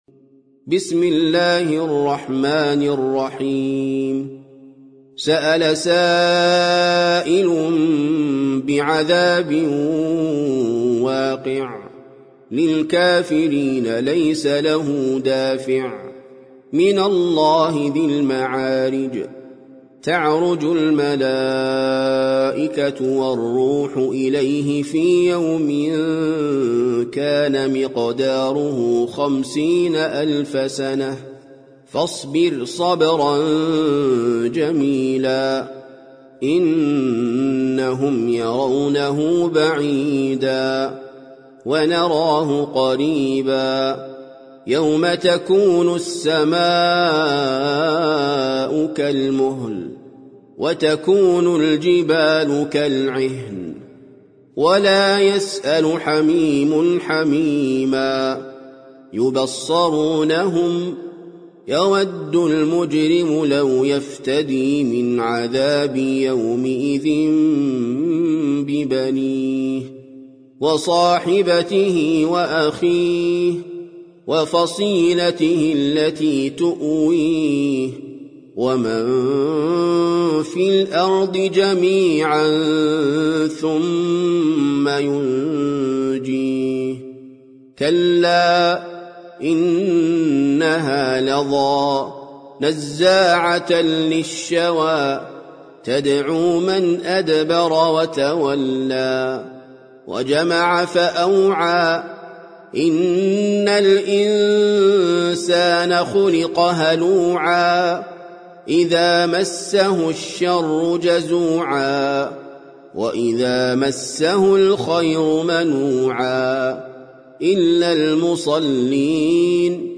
سورة المعارج - المصحف المرتل (برواية حفص عن عاصم)
جودة عالية